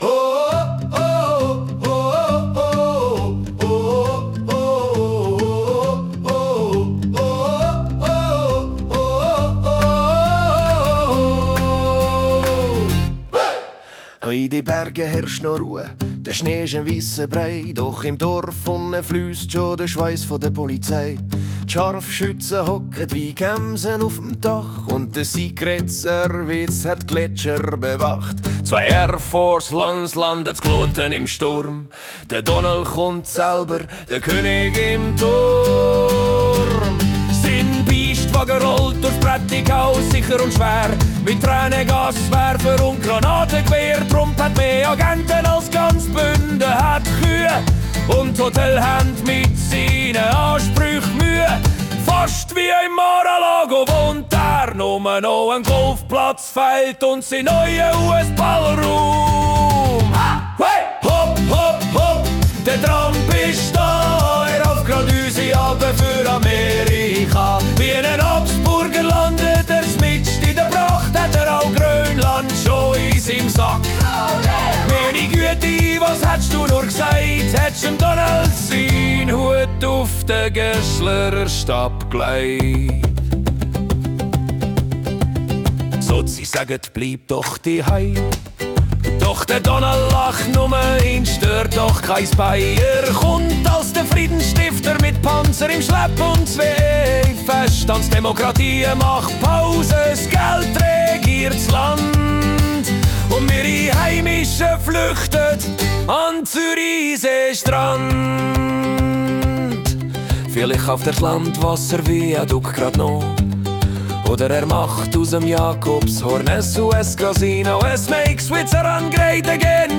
Ich wählte den Stil einer irischen Ballade, aber in Schweizerdeutsch.
Klar, der Schweizer Dialekt wirkt etwas gewöhnungsbedürftig.